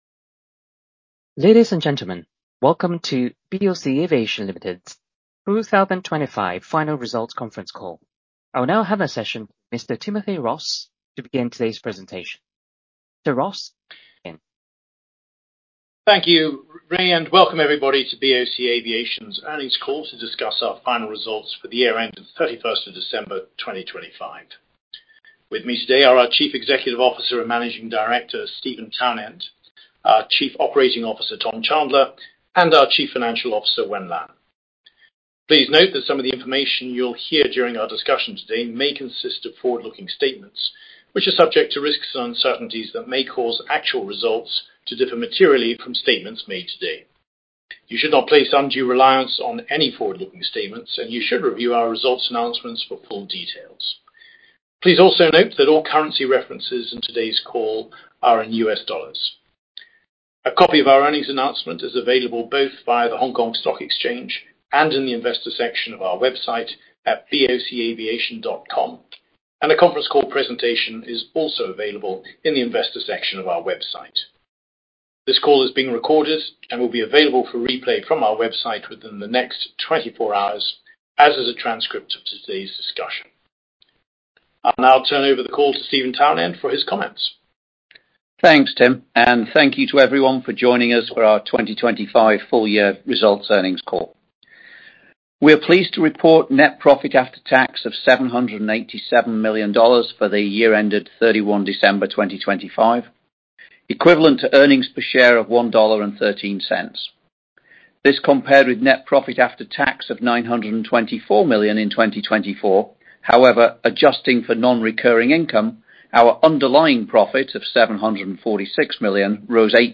业绩回顾电话会议录音